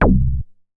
MoogFace 010.WAV